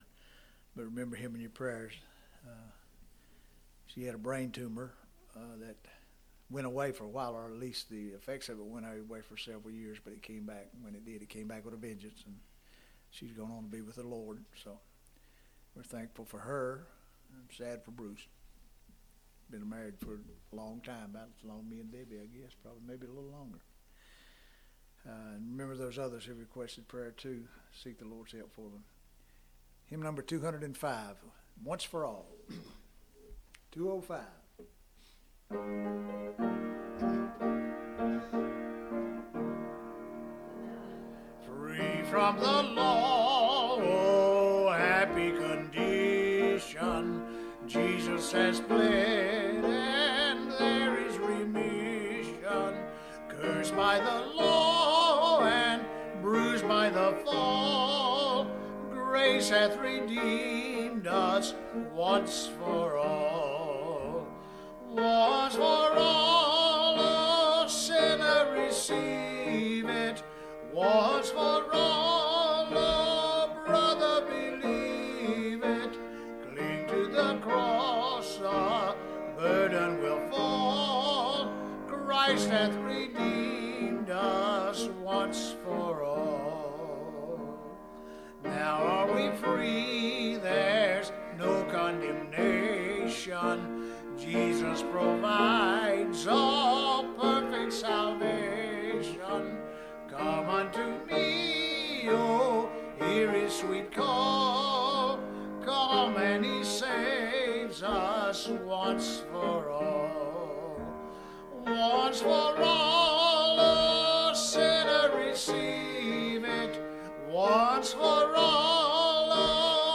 Introduction to Exodus | SermonAudio Broadcaster is Live View the Live Stream Share this sermon Disabled by adblocker Copy URL Copied!